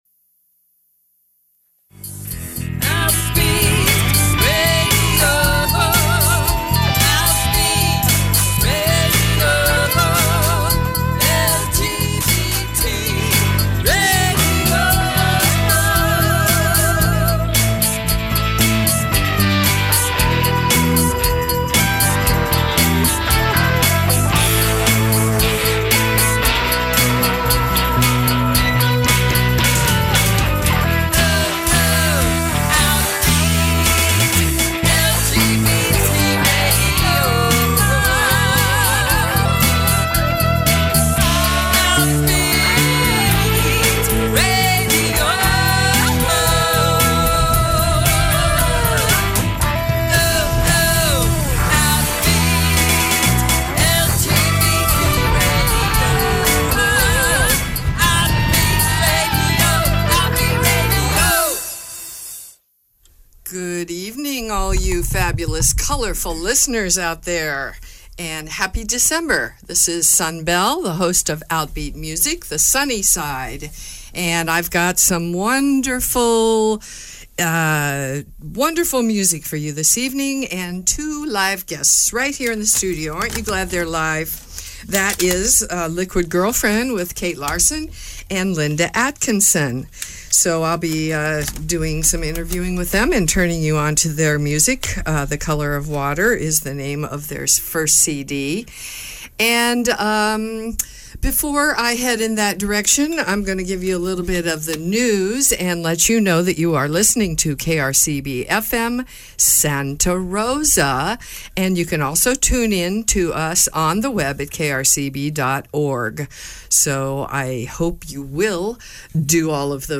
singer, songwriter, musicians. In studio with Host